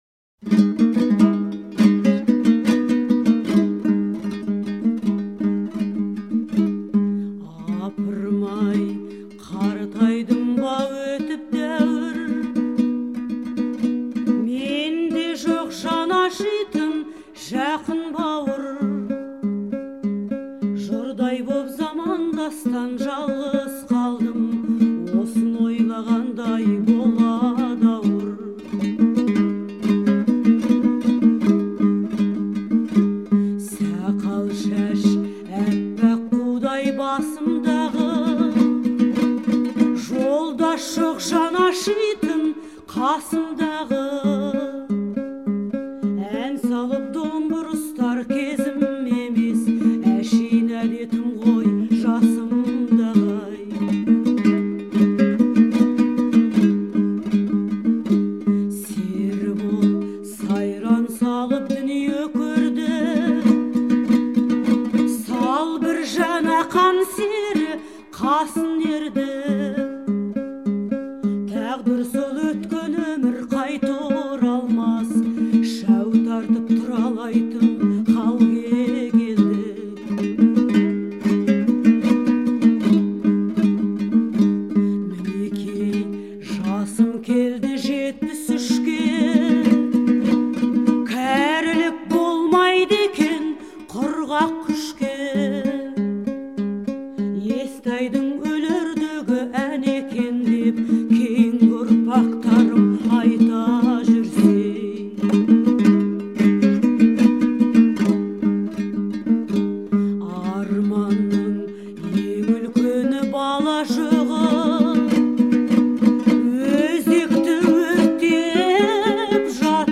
来自天山的遊牧吟唱